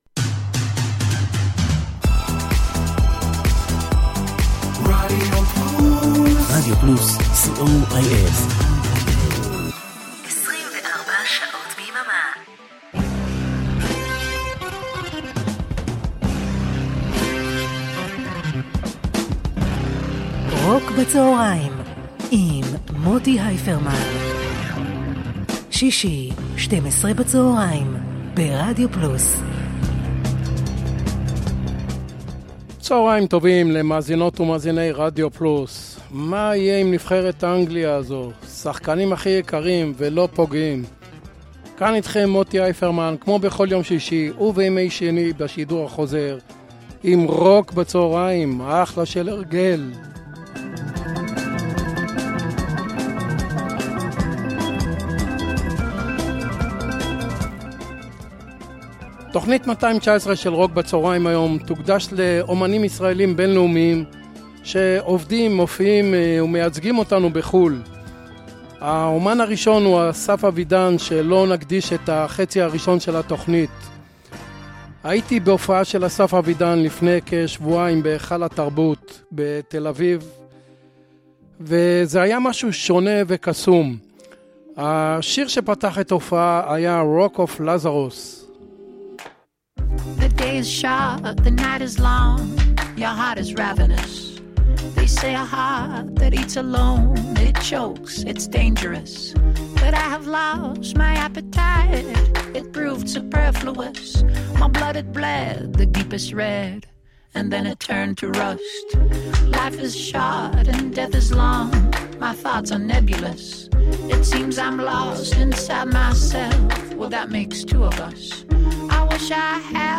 blues rock classic rock indie rock
pop rock